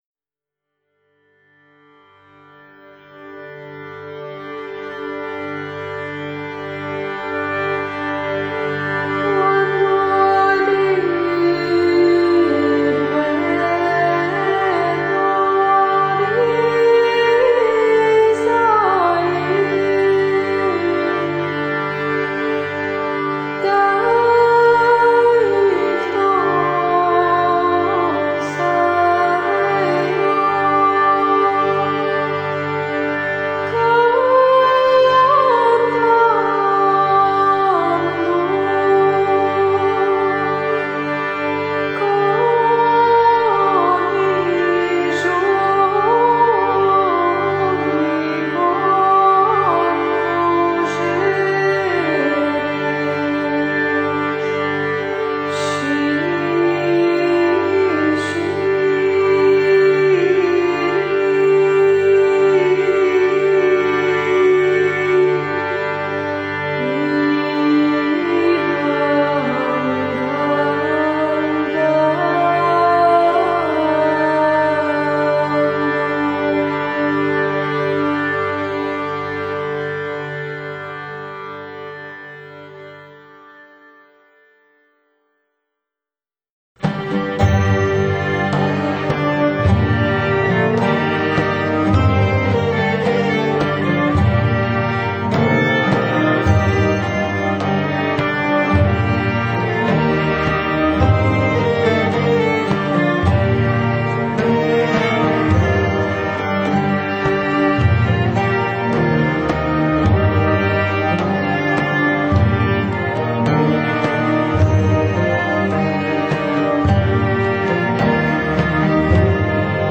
以神秘、低沉又清灵的特殊唱腔，
全片成功融合了阿拉伯、中世纪与精致的电子乐声，空灵的古代圣歌搭配
器乐幻想曲般的陪衬，让人产生独行於中世纪欧洲石巷中的错觉。
他们的音乐就像点着昏暗烛光的地下墓穴，古老而神秘.